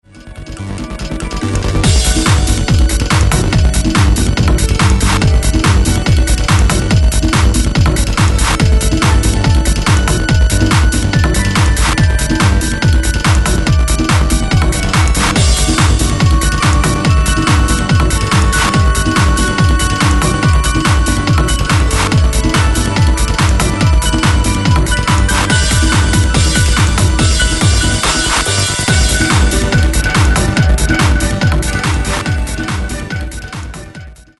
エレクトロニカからテクノまで手がける。